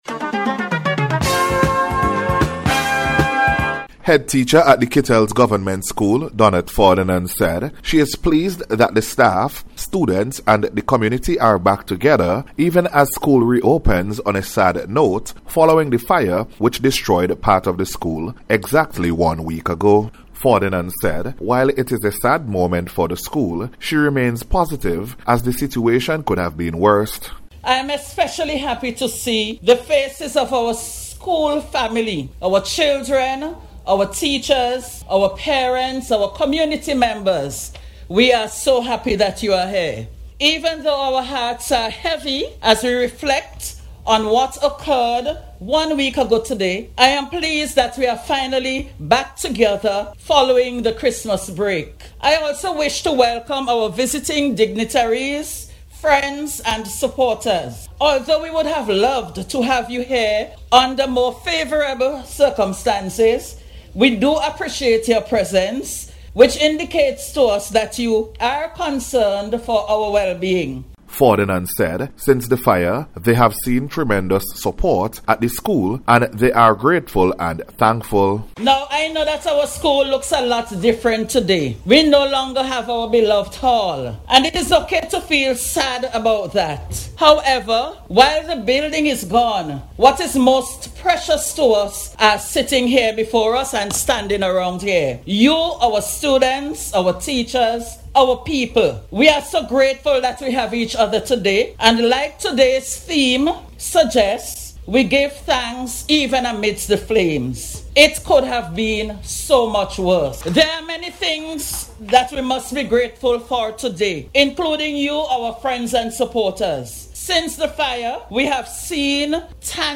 NBC’s Special Report- Friday 9th January,2025